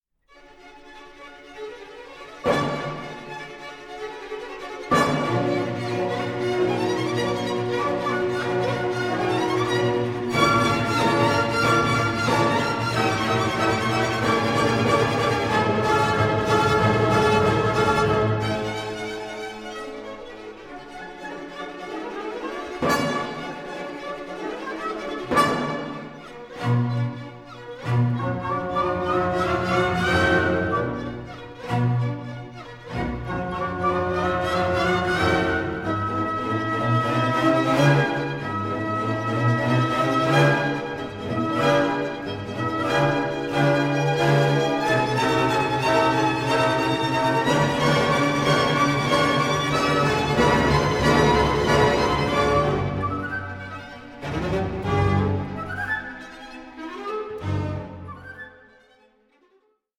light, lyrical, and full of Italianizing elements.
period instruments, transparent, but full of fire.